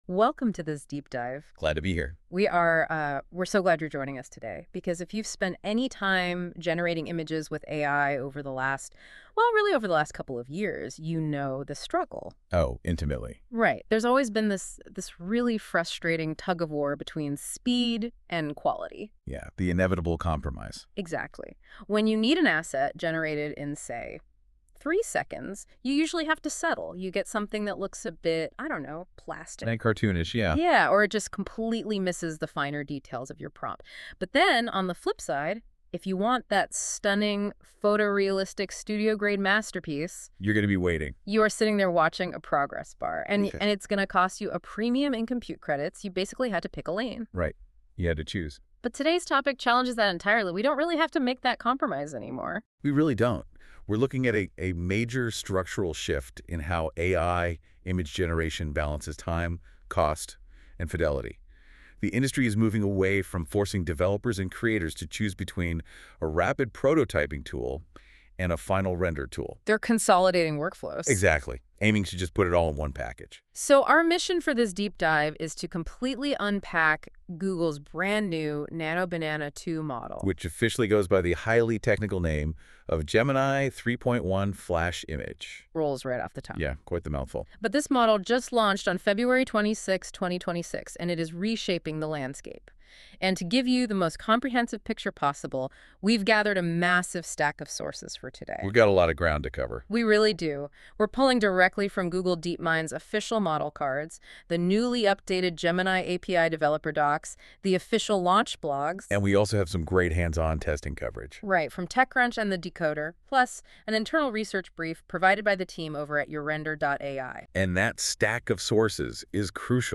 Podcast: "Google's Nano Banana 2" (debate en ingles)